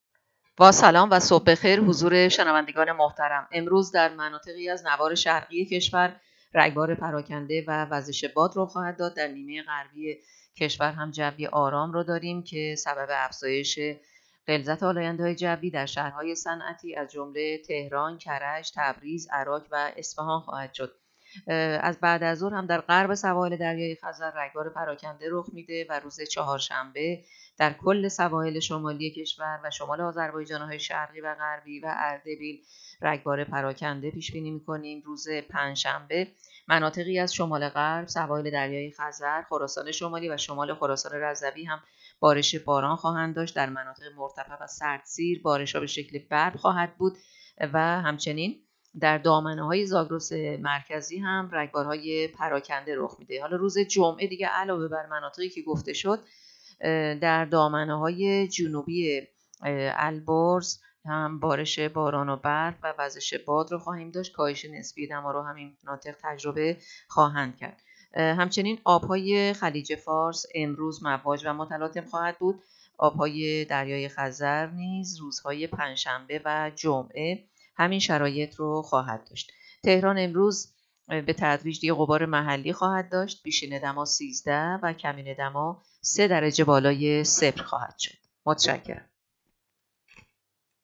گزارش آخرین وضعیت جوی کشور را از رادیو اینترنتی پایگاه خبری وزارت راه و شهرسازی بشنوید.
گزارش رادیو اینترنتی پایگاه‌ خبری از آخرین وضعیت آب‌وهوای ۲۵ دی؛